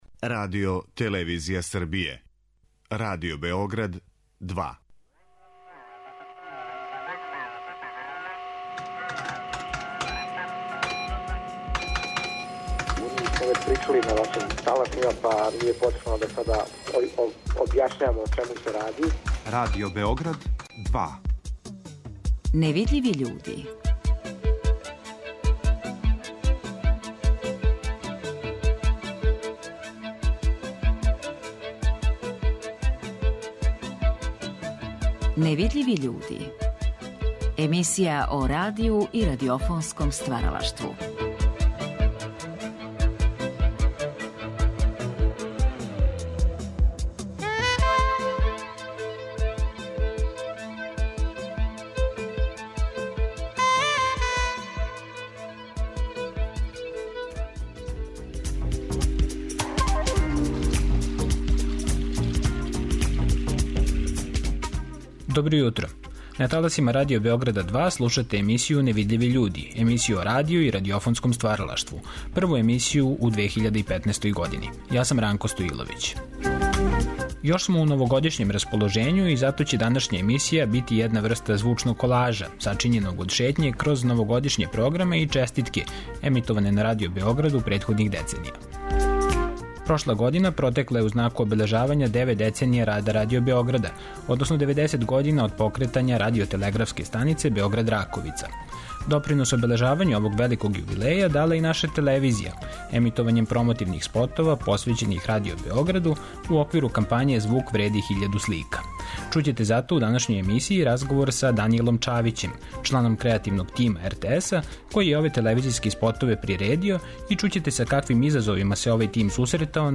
Прва емисија 'Невидљиви људи' у 2015. години биће једна врста звучног колажа сачињеног од шетње кроз Новогодишње програме и честитке емитоване на Радио Београду претходних деценија.